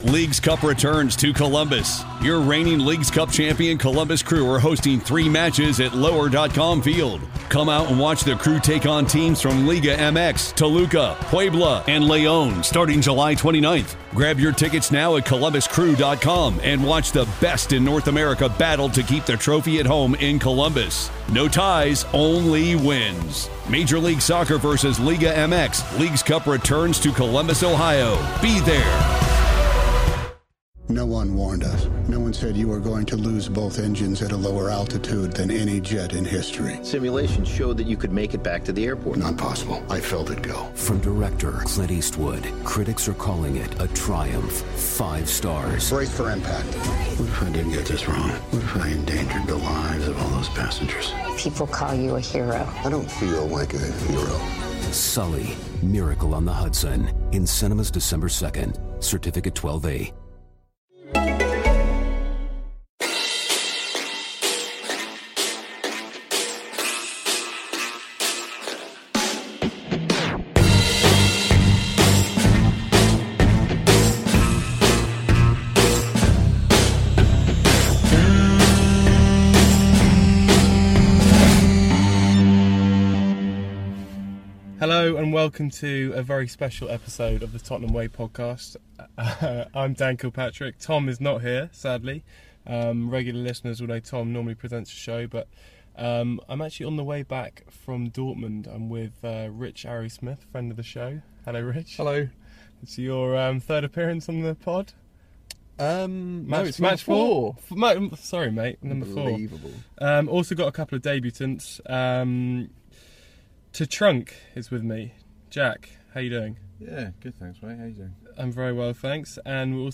on his way back from Dortmund